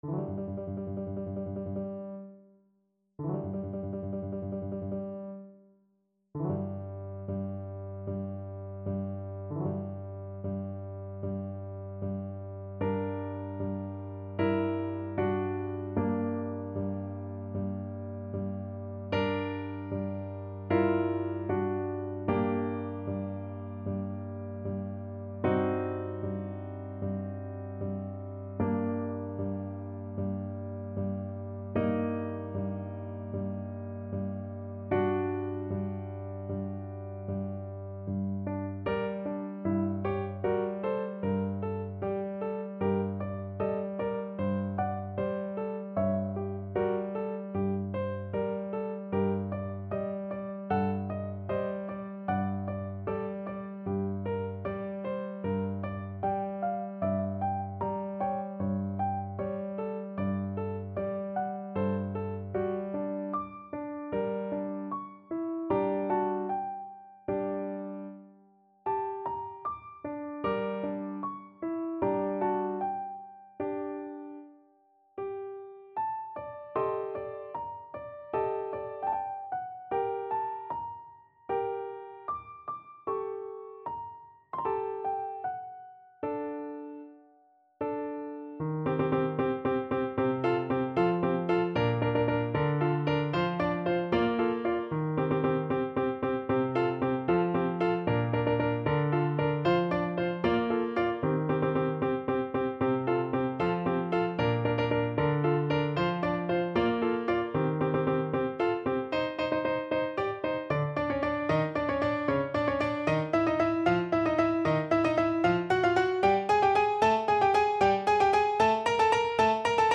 Play (or use space bar on your keyboard) Pause Music Playalong - Piano Accompaniment Playalong Band Accompaniment not yet available reset tempo print settings full screen
= 76 Moderato in modo di marcia funebre
4/4 (View more 4/4 Music)
G minor (Sounding Pitch) (View more G minor Music for Flute )